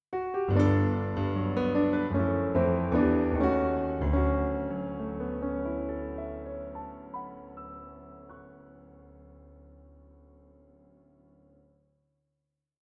ジングル